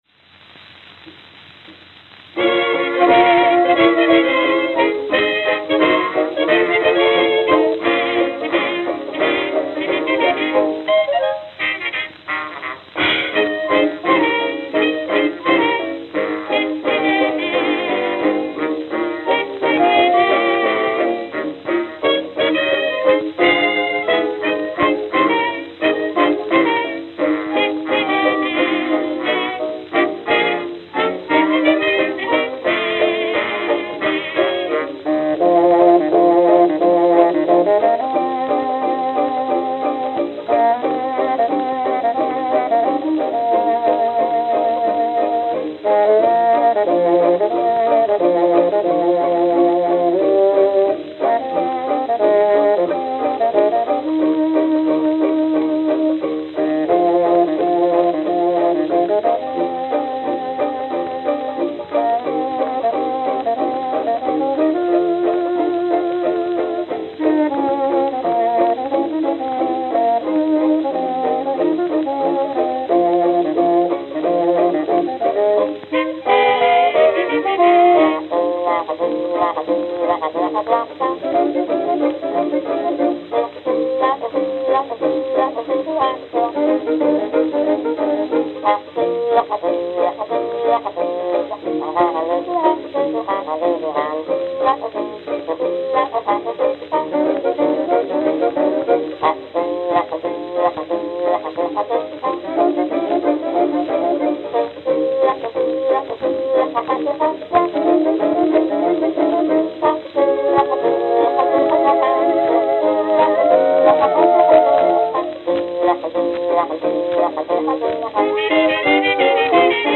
Note: Two baton taps before start.